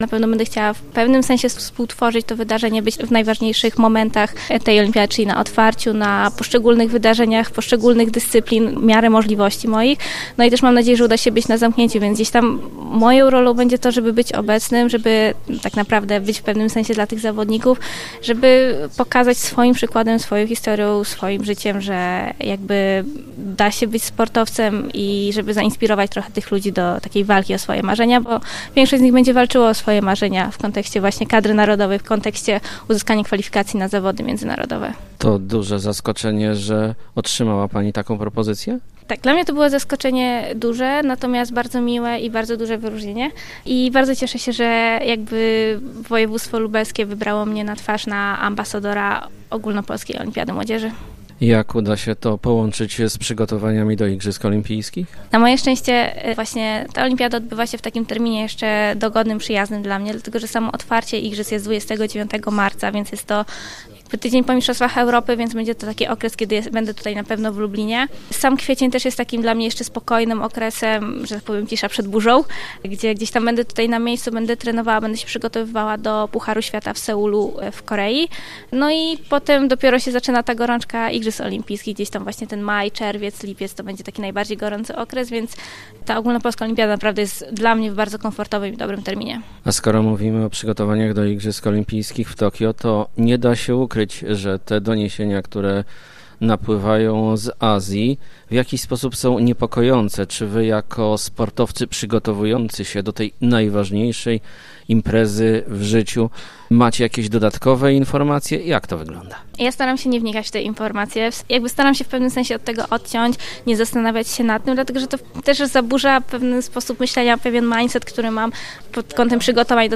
Na czym będzie polegała jej rola – o tym w rozmowie